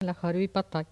Maraîchin
Catégorie Locution